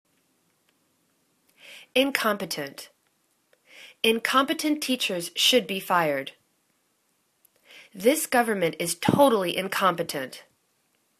in.com.pe.tent      /in'kompətənt/    adj